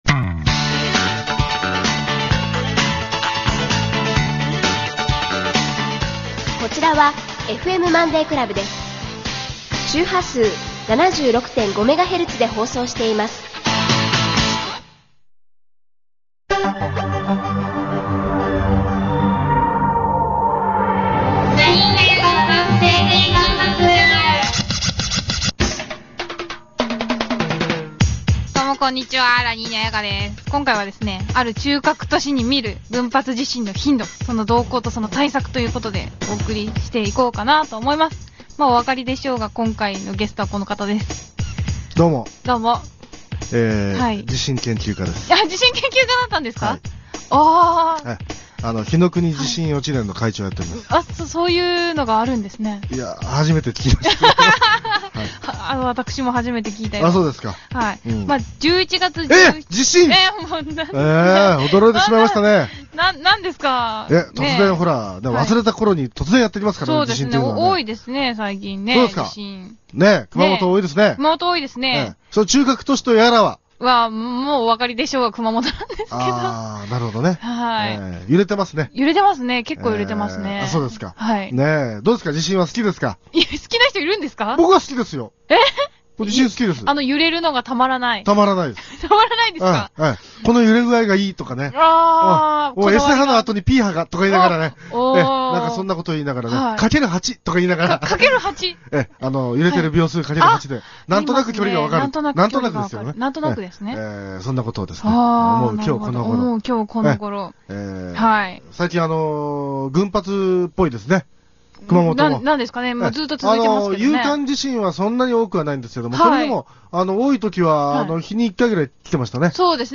・隔週日曜１３：３０〜１３：４０生放送